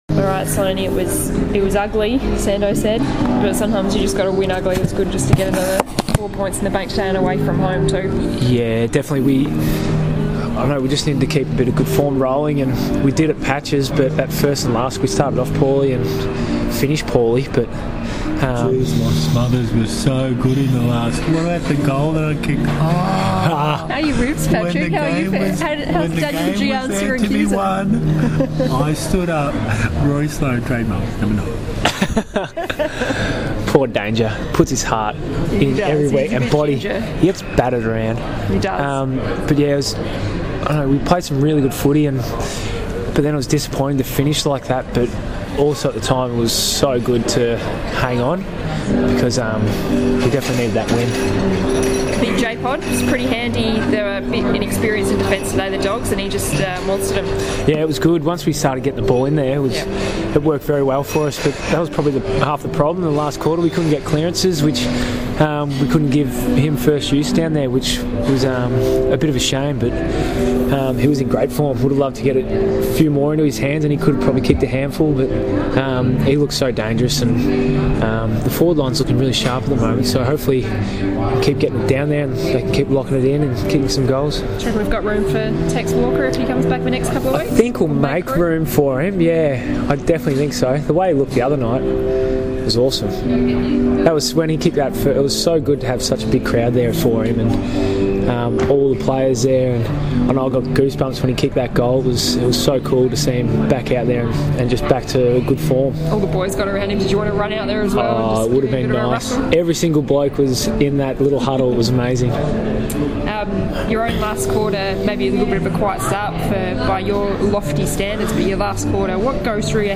Patrick Dangerfield crashes Rory Sloane's post-match interview following Adelaide's gutsy nine-point win over the Bulldogs